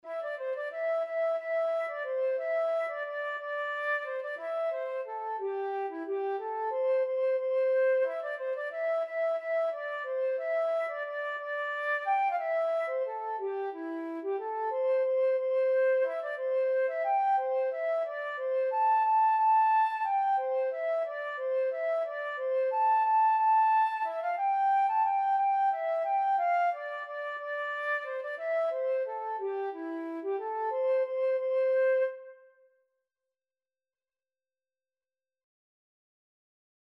3/4 (View more 3/4 Music)
E5-A6
Flute  (View more Intermediate Flute Music)
Traditional (View more Traditional Flute Music)